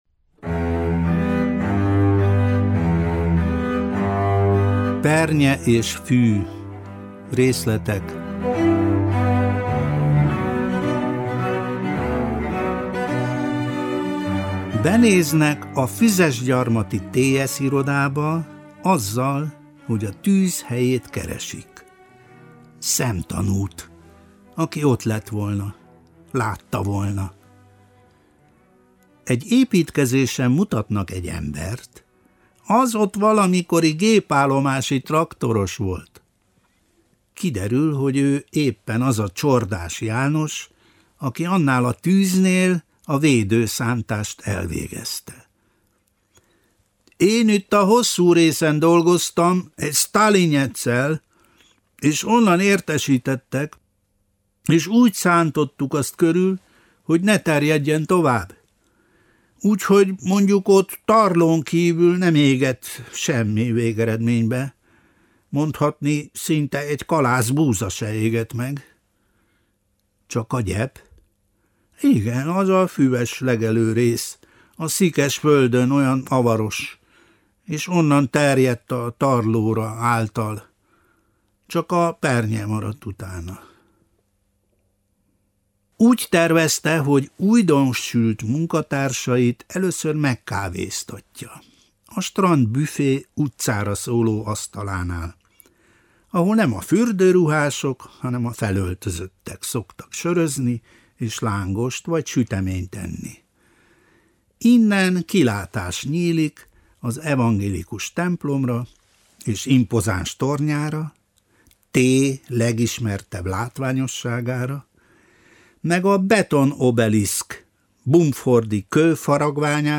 Závada Pál részleteket olvas fel a Pernye és fű című, tavaly megjelent regényéből.